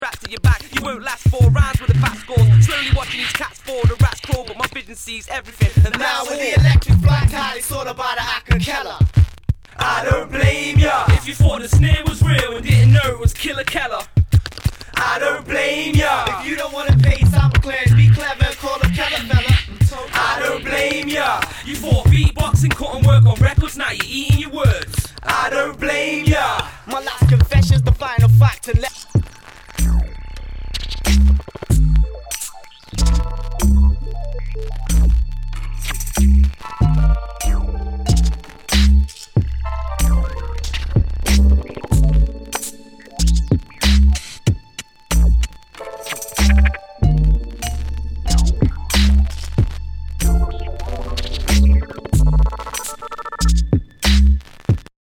Nu- Jazz/BREAK BEATS
ナイス！ダウンテンポ / ブレイクビーツ！
全体にチリノイズが入ります